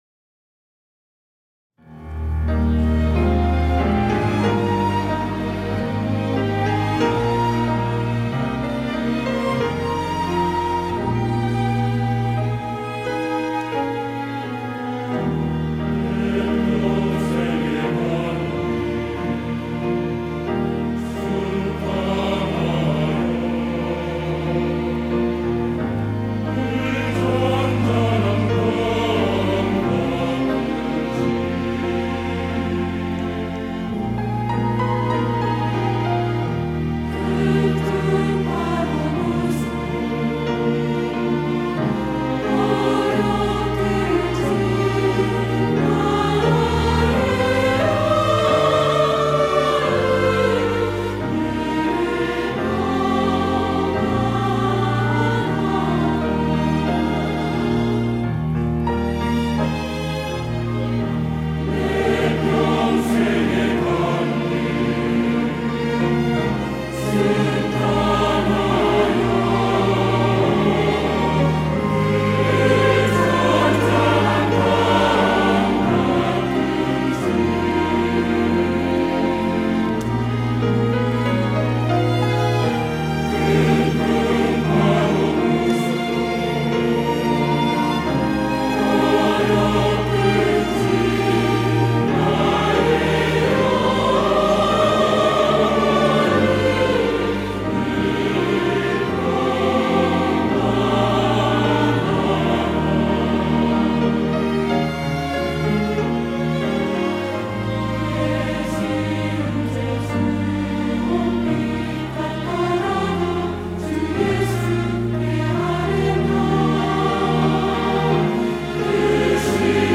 호산나(주일3부) - 내 평생에 가는 길
찬양대